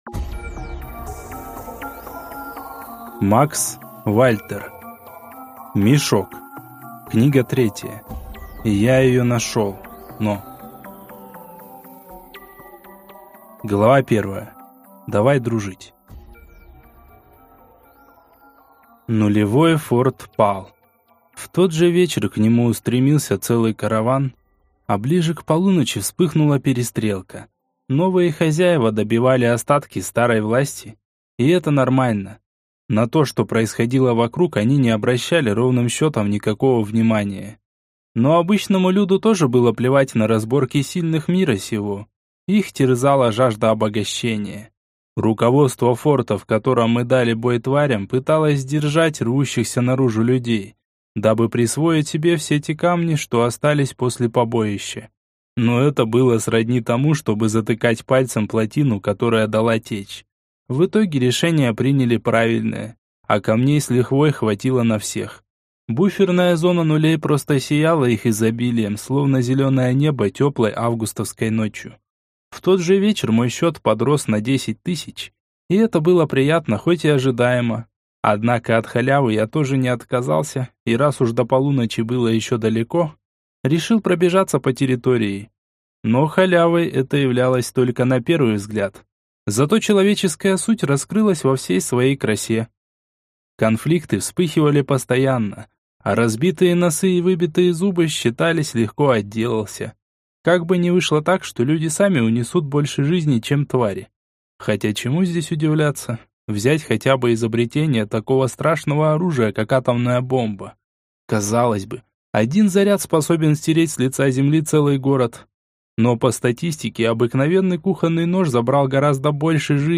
Аудиокнига Я её нашёл, но…